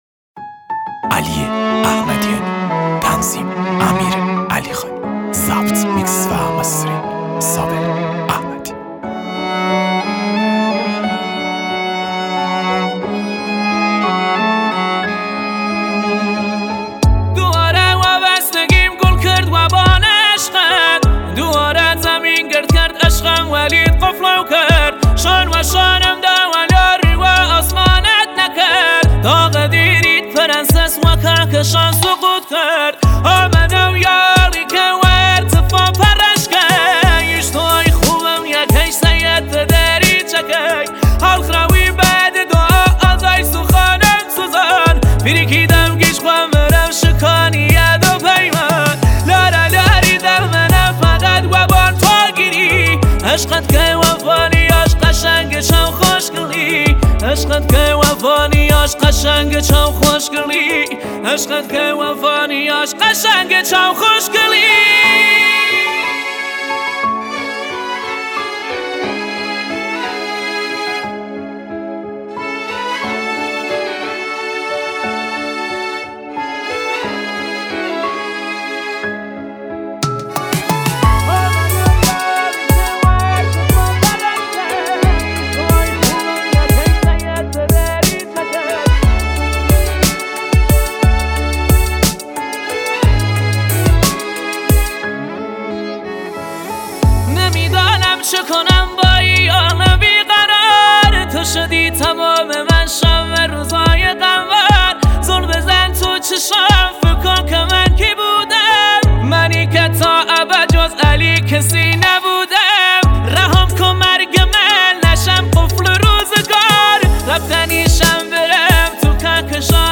شنیدنی و احساسی
با صدای دلنشین و تنظیم جدید